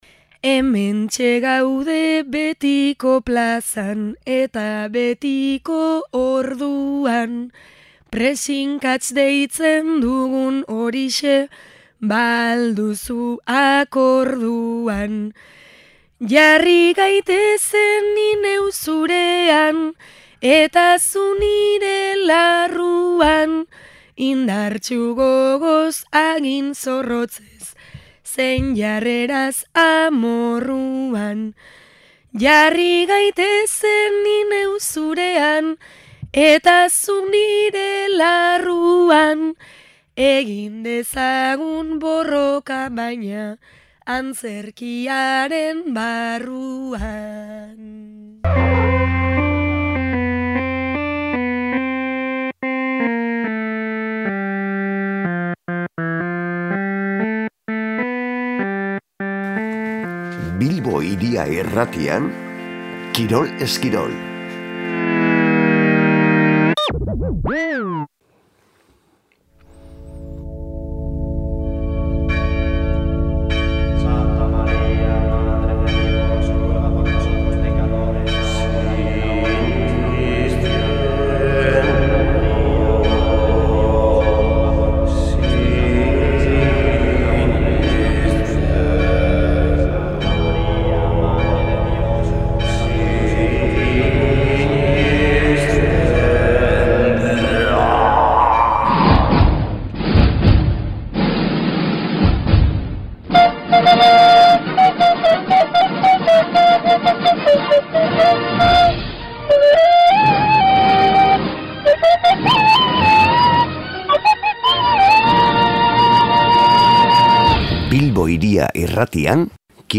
Ring gaineko borroka-antzezpen koreografiatua, Bilbo Hiria irratiaren KIROL ESKIROL saioan.